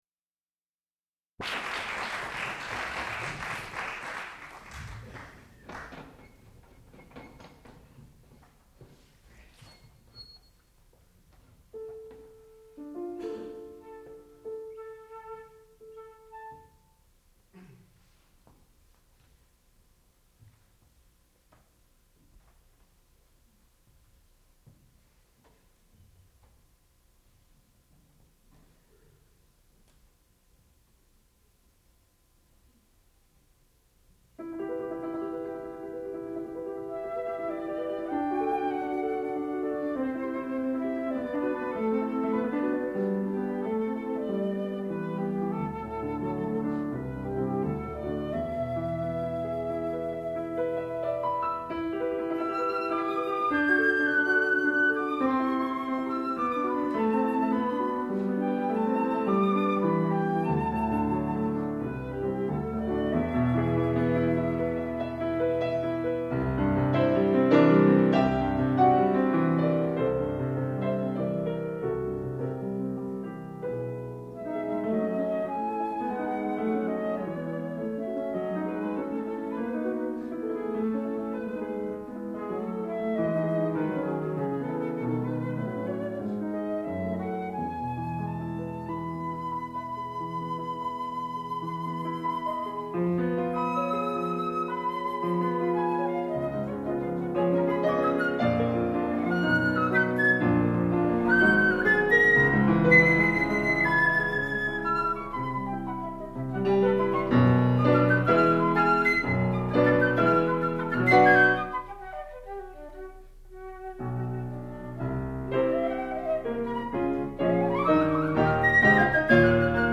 sound recording-musical
classical music
flute
piano
Master's Recital